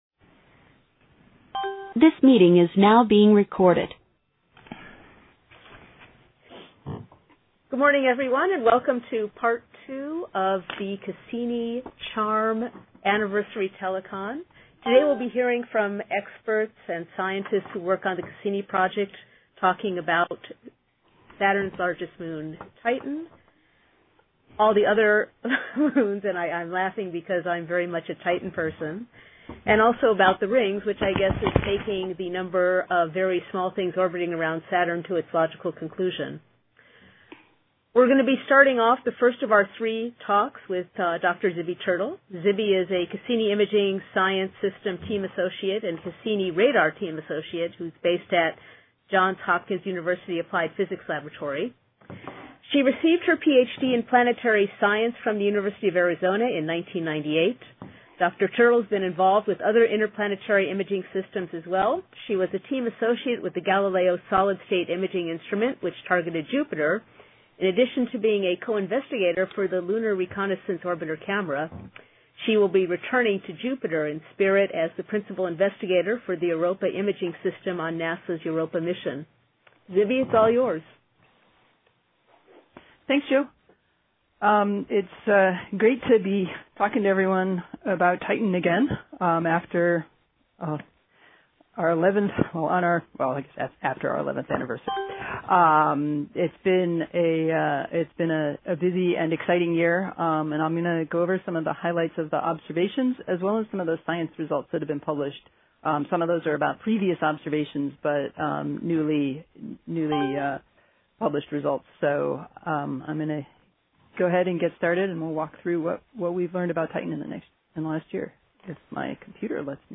This is the second half of the annual CHARM anniversary telecon, in which we review science highlights of the past year from Cassini’s various disciplines.